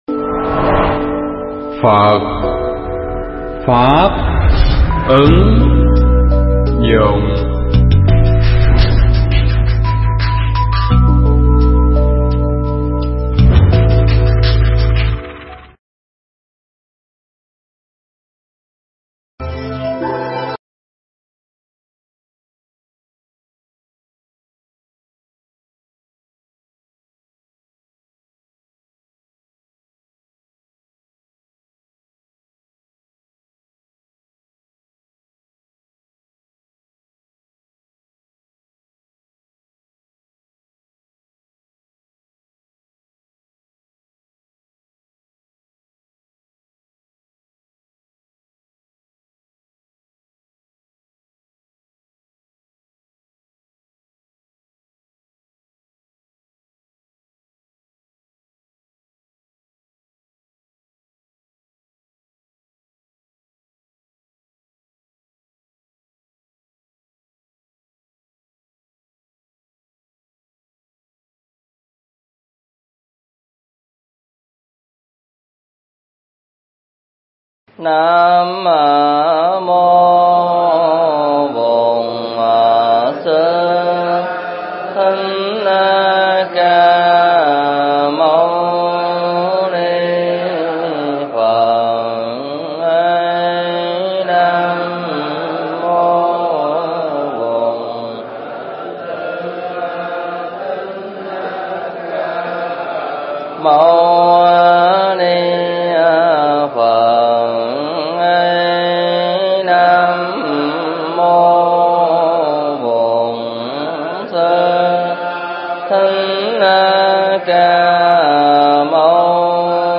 Mp3 pháp thoại Kinh Pháp Bảo Đàn 3
Tu Viện Tường Vân